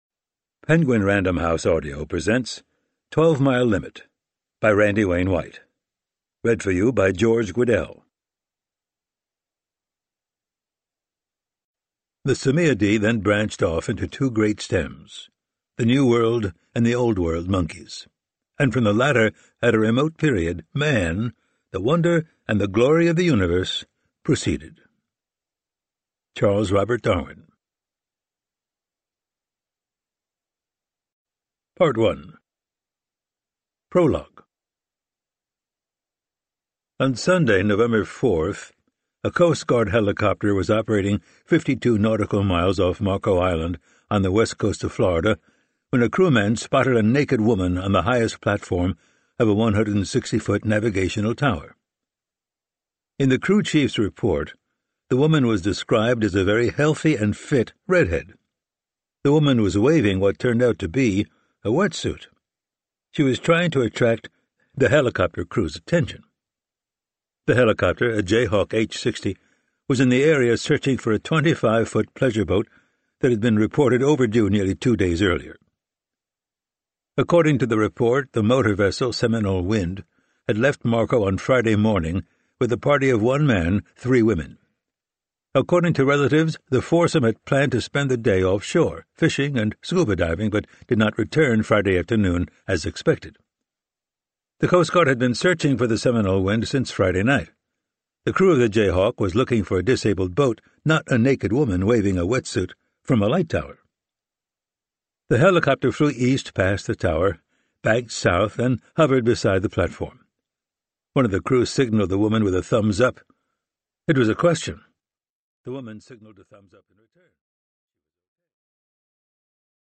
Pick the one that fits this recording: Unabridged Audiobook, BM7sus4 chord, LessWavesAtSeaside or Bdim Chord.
Unabridged Audiobook